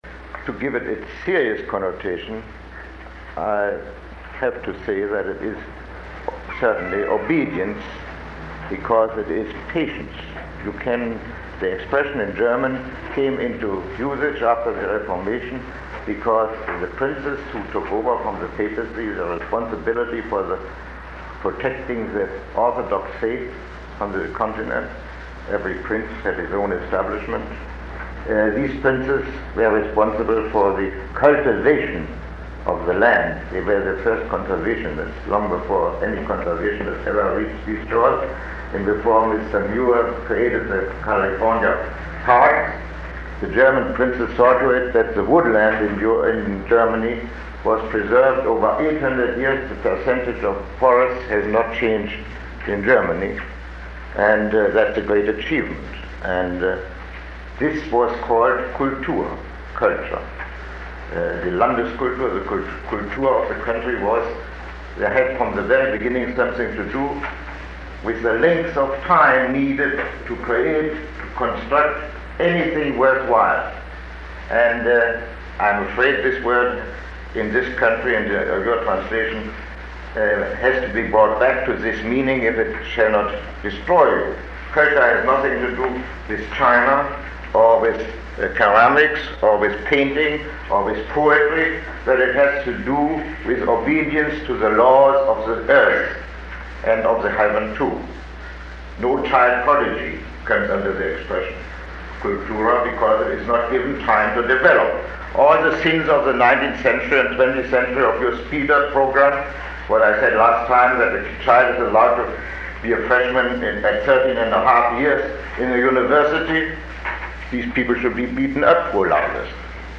Lecture 06